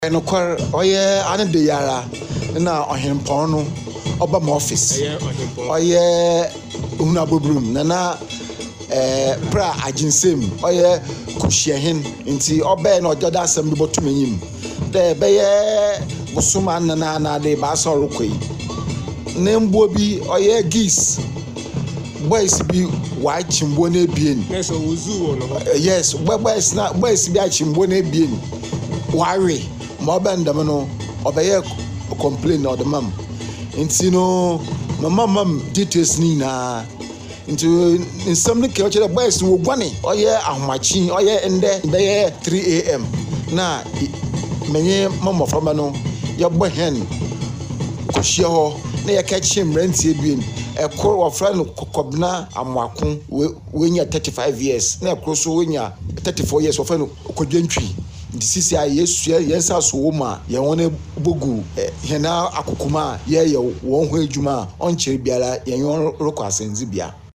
Listen to the police